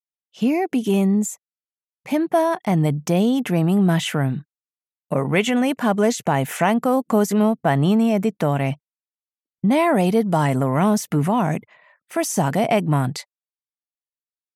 Pimpa and the Daydreaming Mushroom (EN) audiokniha
Ukázka z knihy